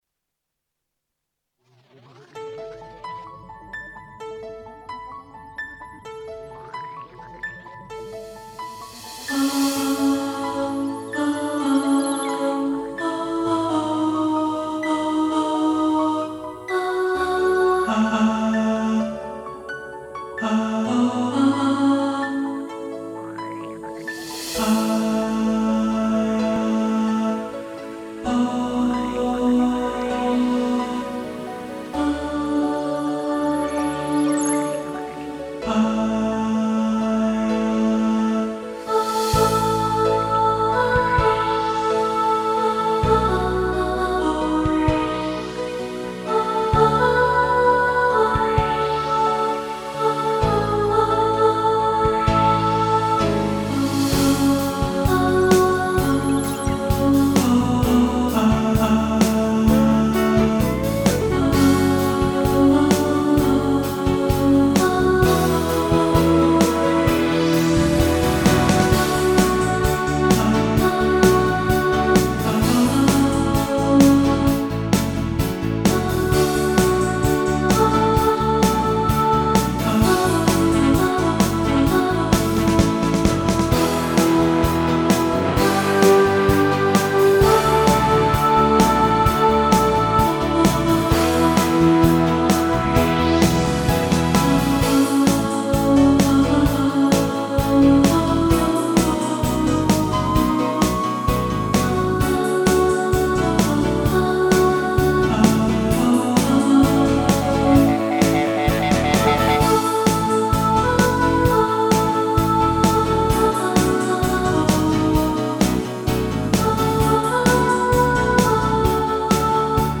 A Kind Of Magic – Alto | Ipswich Hospital Community Choir